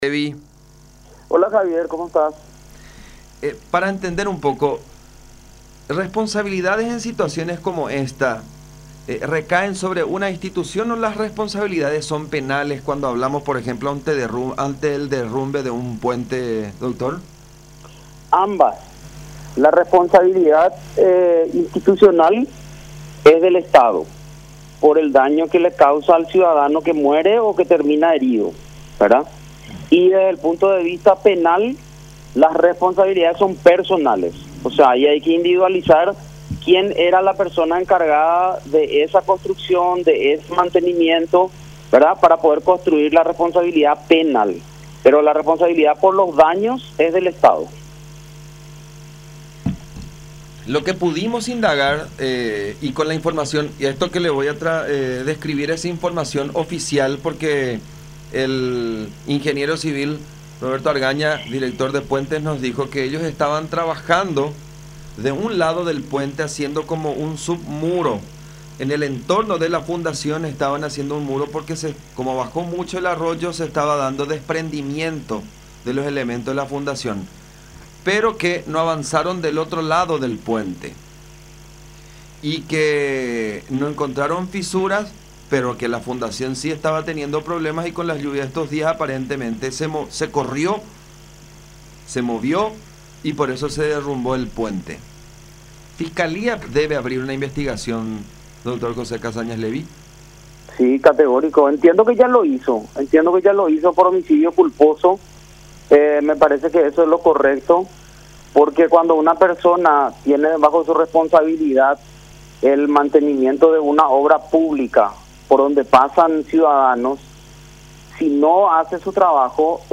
en conversación con Cada Siesta por La Unión